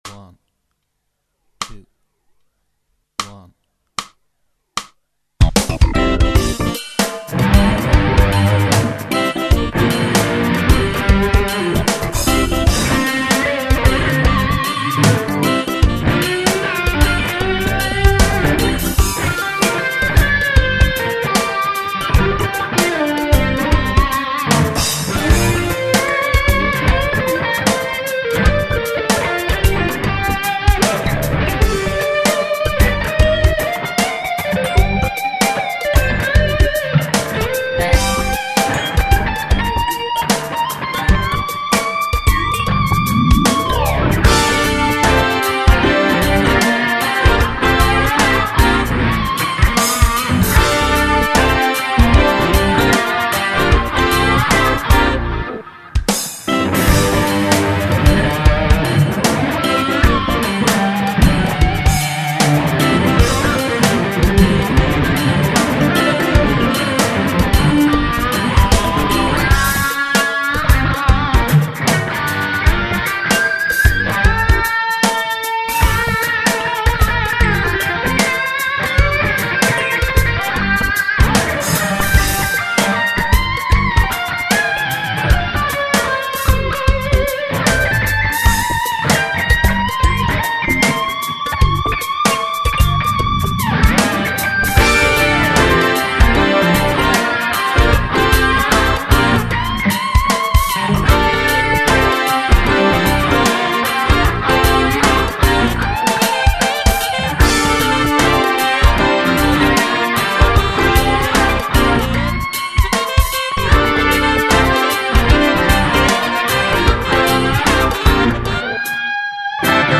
Eingespielt habe ich diesen Jam nach dem Verlust von 2 Saiten (was mir ja schon eeeewig nicht mehr passiert ist) und aller meiner Nerven, mit 3 Gitarren:
Das erste Solo habe ich mit meiner Warmoth Strat gespielt, die Bläser (also den Zwischen- Schlußteil) habe ich zweistimmig mit der Maxxas begleitet und im zweiten Solo gibt es dann meine Tokai Gold Top zu hören.
funky Backingtrack, das Teil groovt ja mächtig und ich kann mir gut vorstellen